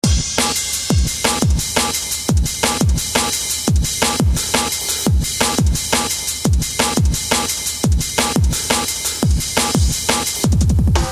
史蒂夫受伤音效
描述：旧版史蒂夫受伤
标签： 史蒂夫 MC 游戏 人声 受伤 我的世界
声道单声道